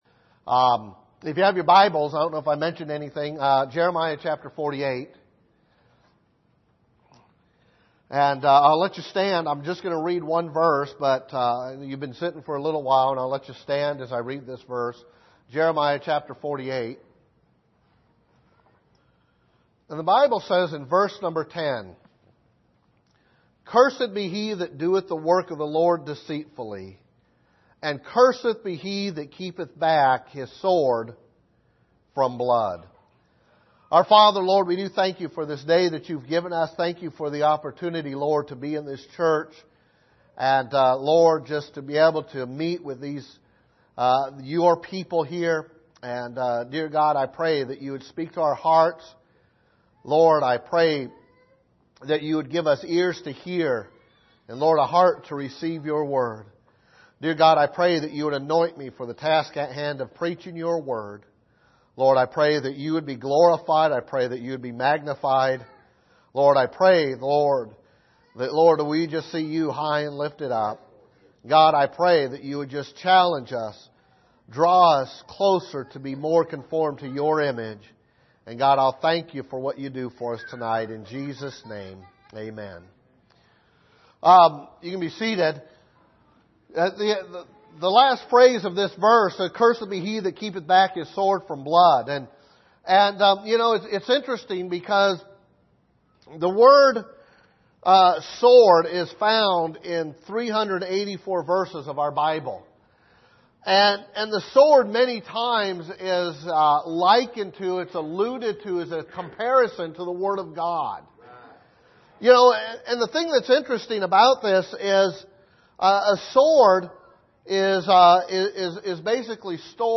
Miscellaneous Passage: Jeremiah 48:10 Service: Sunday Evening Using Your Sword « The Father’s Leadership Roll in the Family Who Hath Believed Our Report